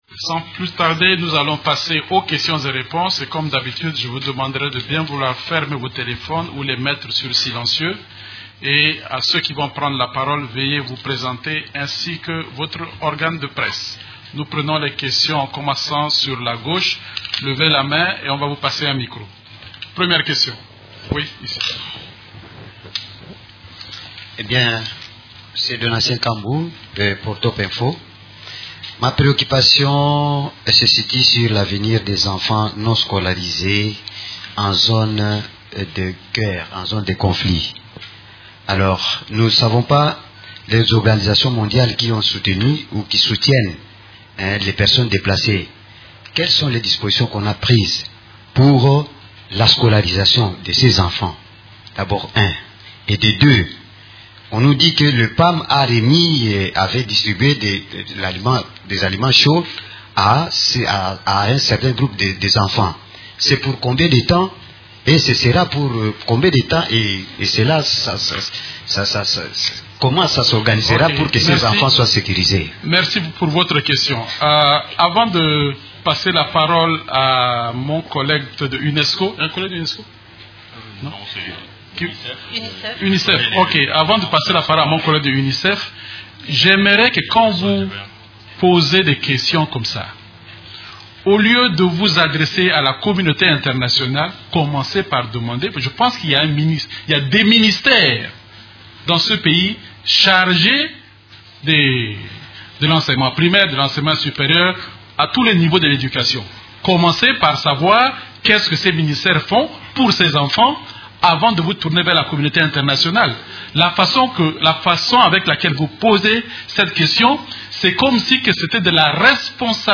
La conférence hebdomadaire des Nations unies du mercredi 7 novembre a porté sur les sujets suivants :